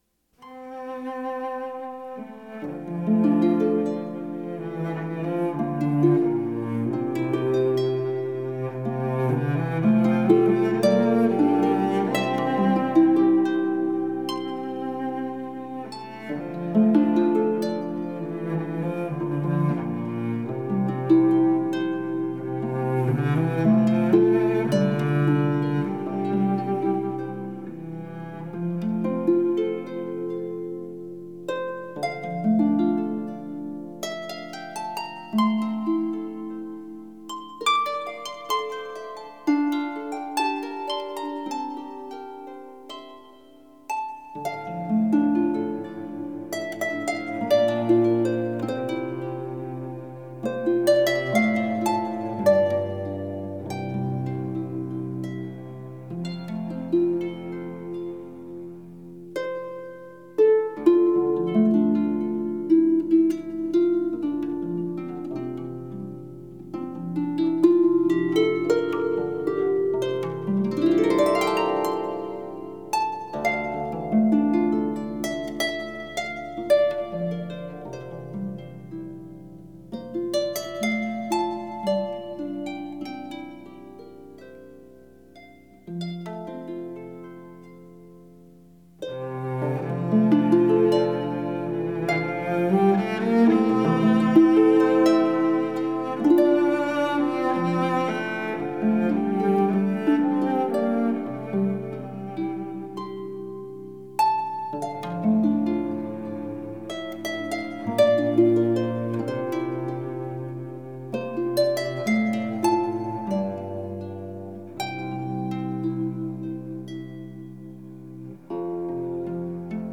立體聲